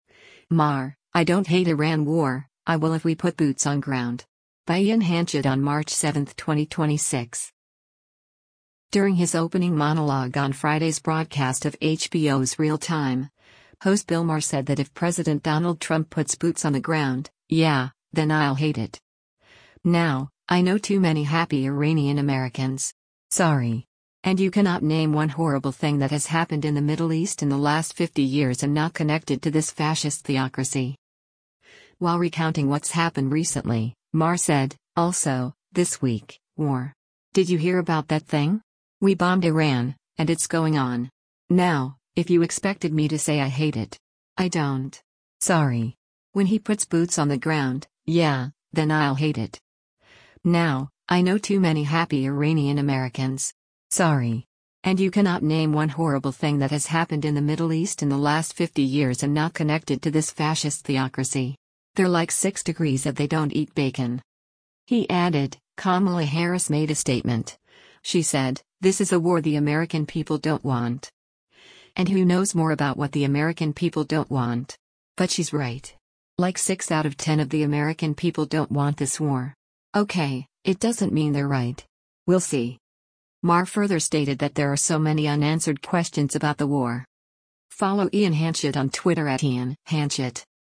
During his opening monologue on Friday’s broadcast of HBO’s “Real Time,” host Bill Maher said that if President Donald Trump “puts boots on the ground, yeah, then I’ll hate it. Now, I know too many happy Iranian Americans. Sorry. And you cannot name one horrible thing that has happened in the Middle East in the last 50 years and not connect it to this fascist theocracy.”